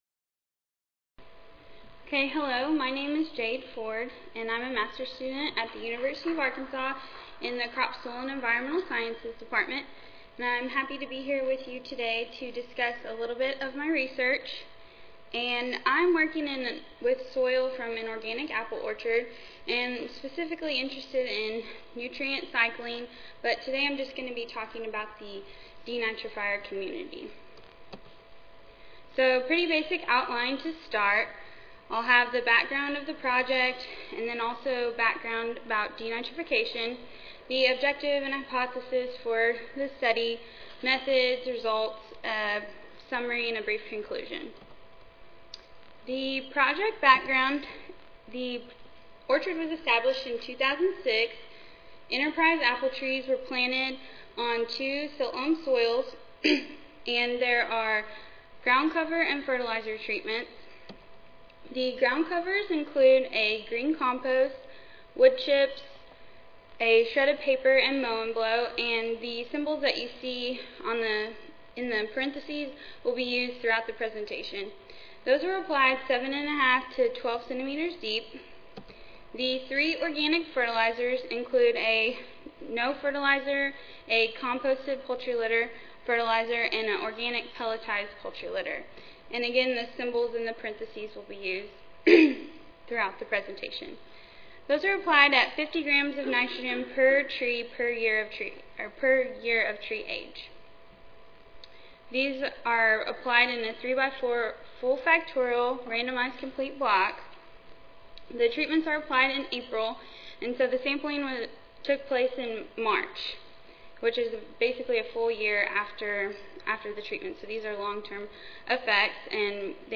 University of Arkansas Audio File Recorded Presentation